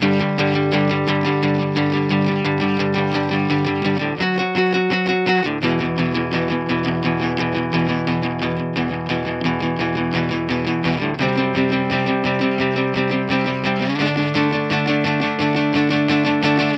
The guitar is tuned to drop D (D, A, D, G, B, E)